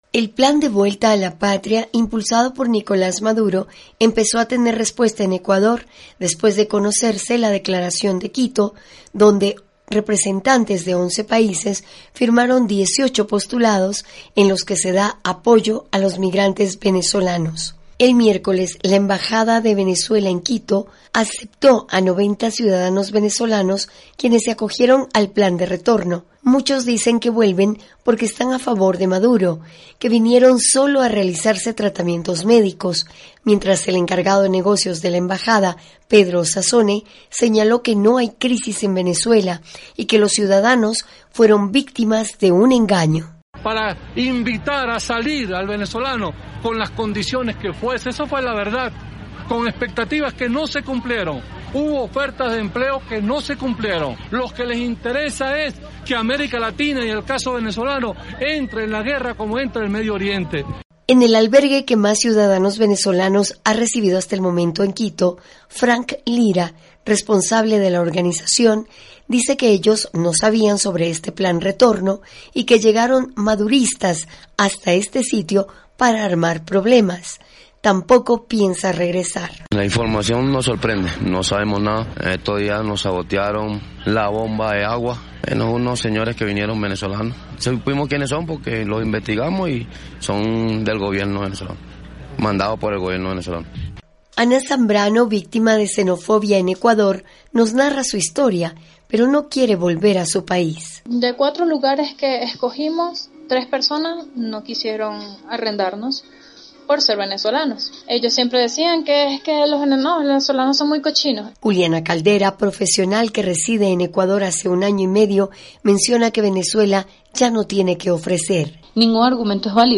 VOA: Informe de Ecuador